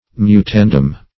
Search Result for " mutandum" : The Collaborative International Dictionary of English v.0.48: mutandum \mu*tan"dum\ (m[-u]*t[a^]n"d[u^]m), n.; pl.